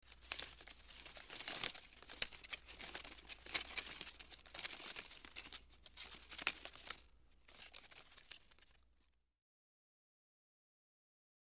Leaves Rustle, Individual; Close Individual Leaves Rolling By And Rustling. - Rustling Leaves